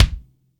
MIX KICK1.wav